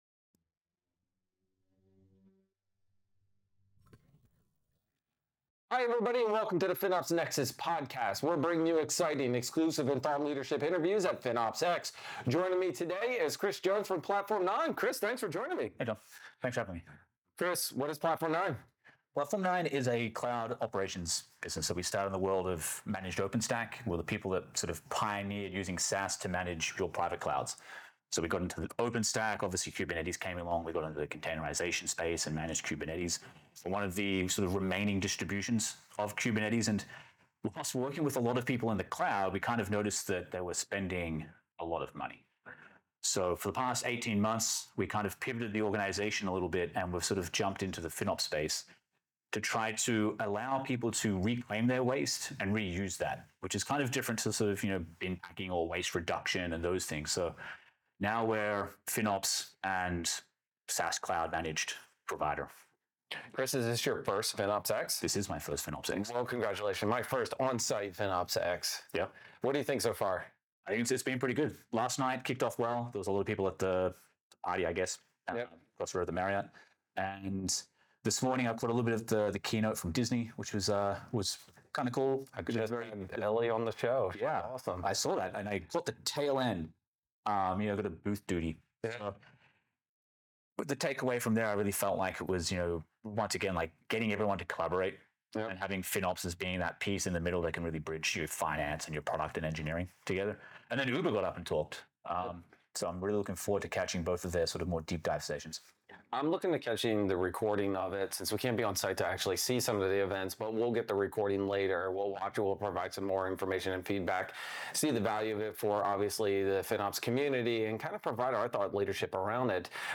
Join us for an exclusive interview
recorded live at the FinOps NEXUS event during FinOps X!